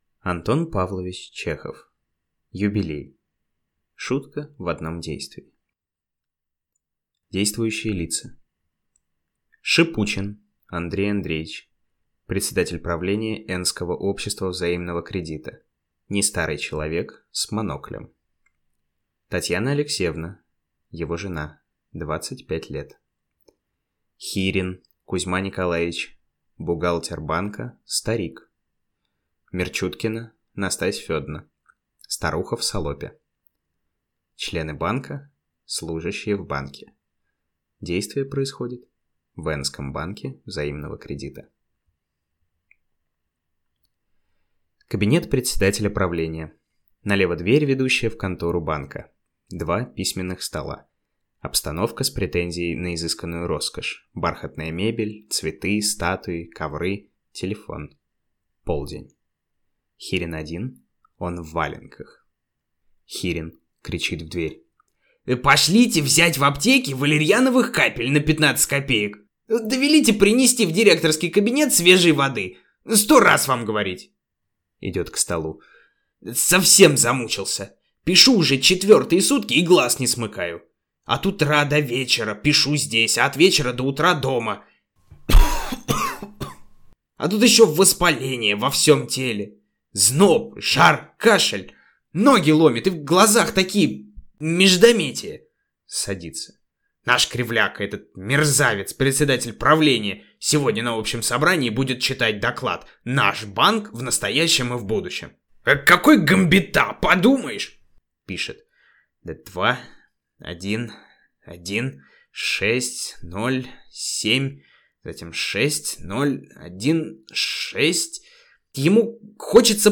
Аудиокнига Юбилей | Библиотека аудиокниг